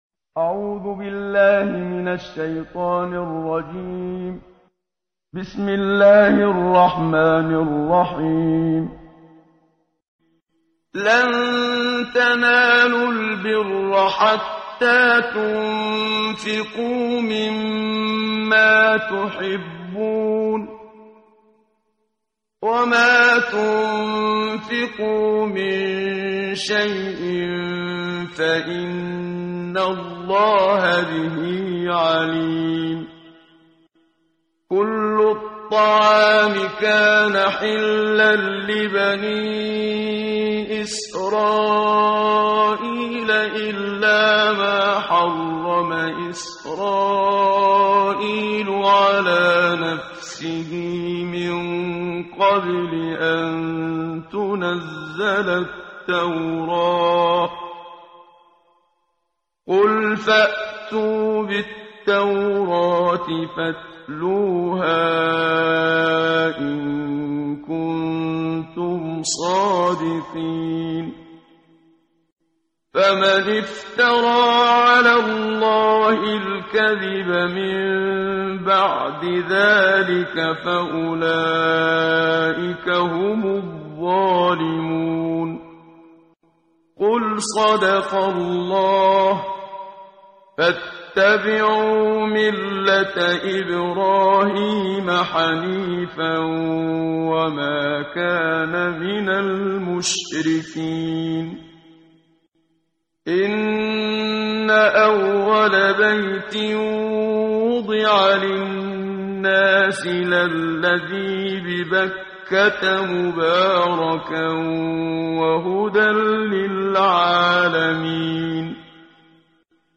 قرائت قرآن کریم ، صفحه 62، سوره مبارکه آلِ عِمرَان آیه 92 تا 100 با صدای استاد صدیق منشاوی.